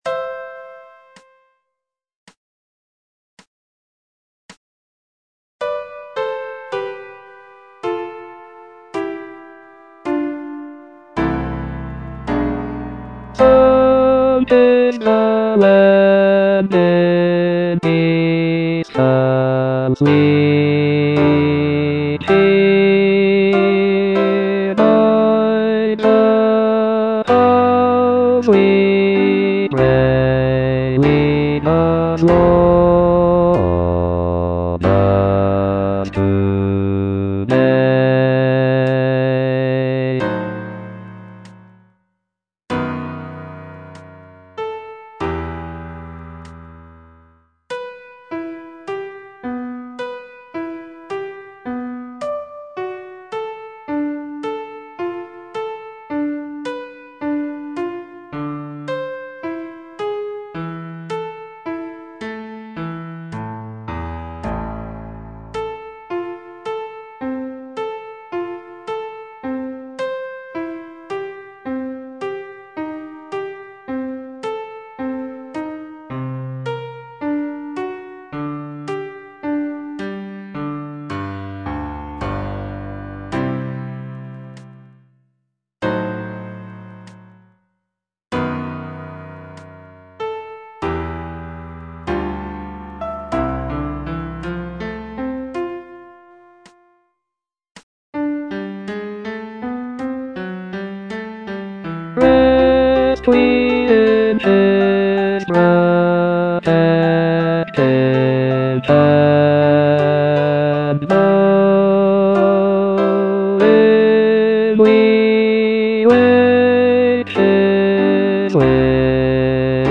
bass I) (Voice with metronome